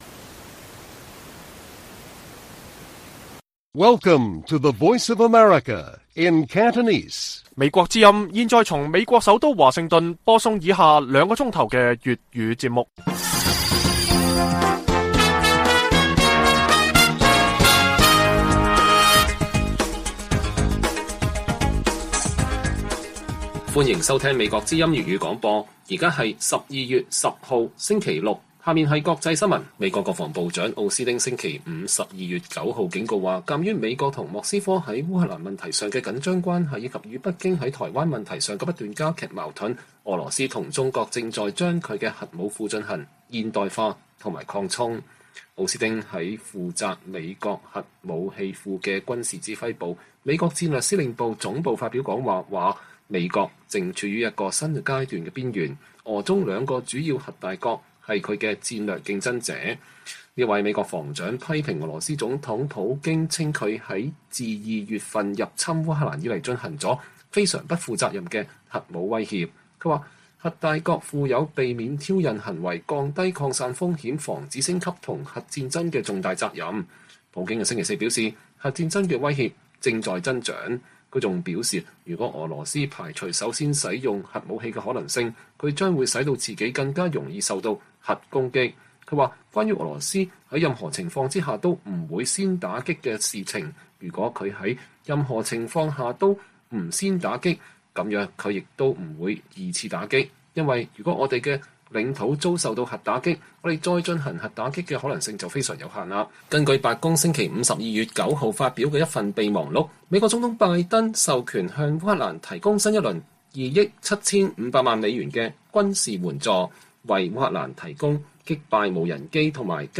粵語新聞 晚上9-10點 ： 防疫鬆綁後政治局強調穩經濟 中國地方官員商家爭相出國搶單